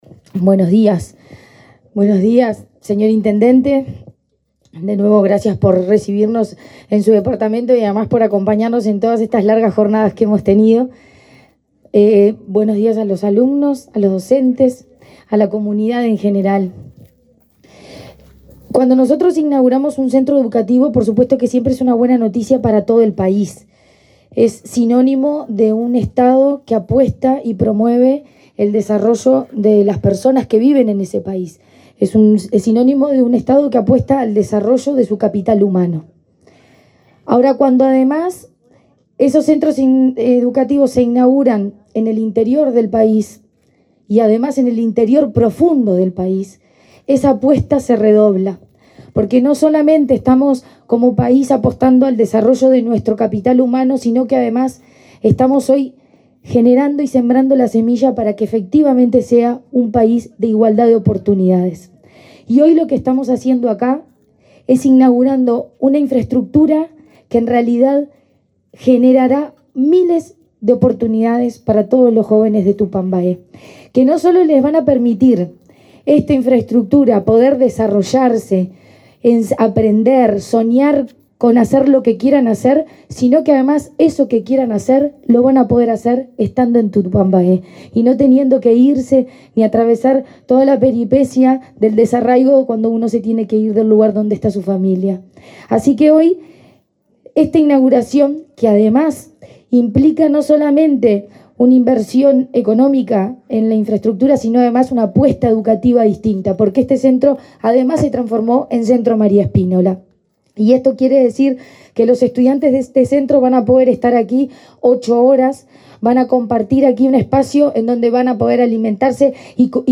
Acto de inauguración de las obras en el liceo de Tupambaé
En el evento participaron la presidenta de la ANEP, Virginia Cáceres, y la directora nacional de Secundaria, Jenifer Cherro.